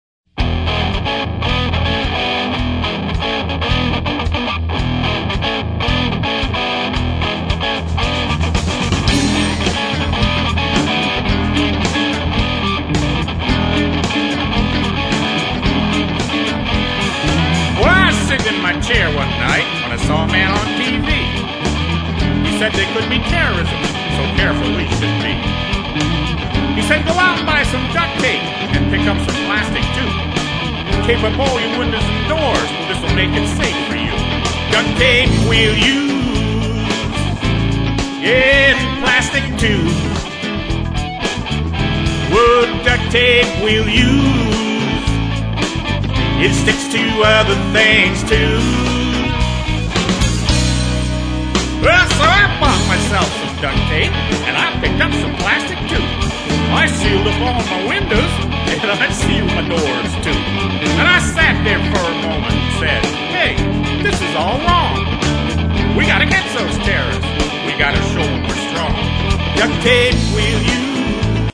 mp3 / Alt Country